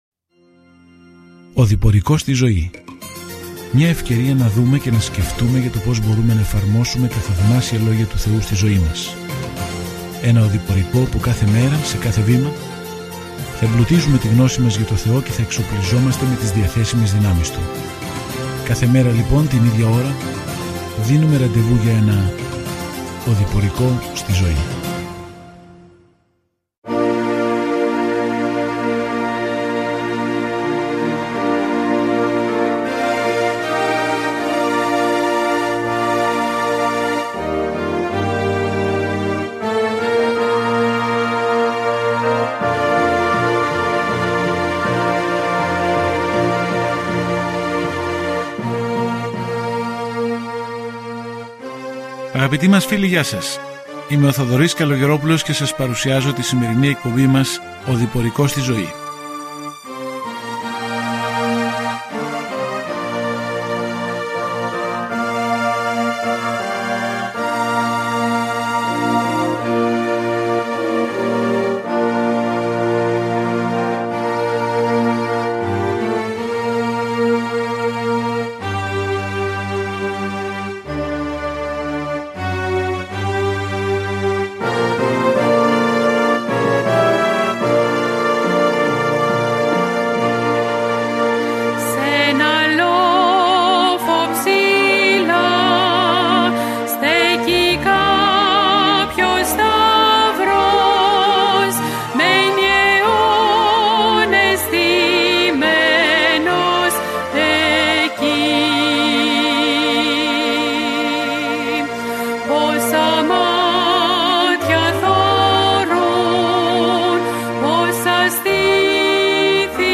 Κείμενο ΚΑΤΑ ΜΑΡΚΟΝ 15:6-39 Ημέρα 26 Έναρξη αυτού του σχεδίου Ημέρα 28 Σχετικά με αυτό το σχέδιο Το συντομότερο Ευαγγέλιο του Μάρκου περιγράφει την επίγεια διακονία του Ιησού Χριστού ως τον πάσχοντα Υπηρέτη και Υιό του Ανθρώπου. Καθημερινά ταξιδεύετε στον Μάρκο καθώς ακούτε την ηχητική μελέτη και διαβάζετε επιλεγμένους στίχους από τον λόγο του Θεού.